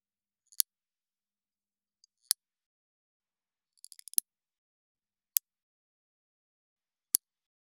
170.爪を切る【無料効果音】
効果音